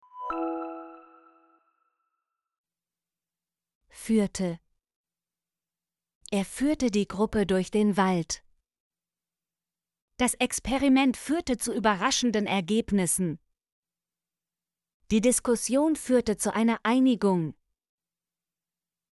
führte - Example Sentences & Pronunciation, German Frequency List